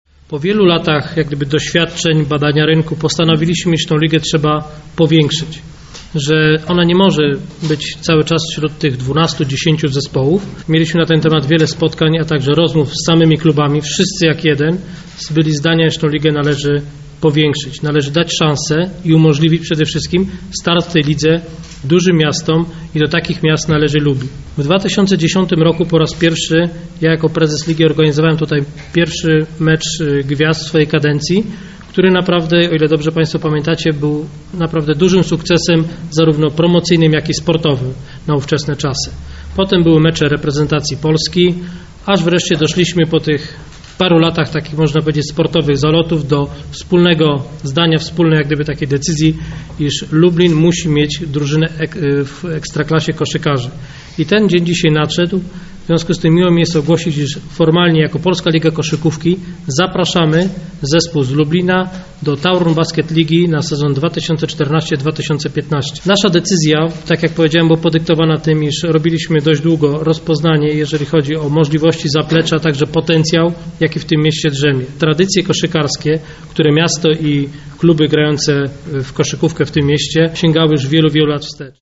Informacje o przystąpieniu koszykarzy Wikany Startu do ekstraklasowych zmagań przedstawiono podczas konferencji prasowej w lubelskim ratusz.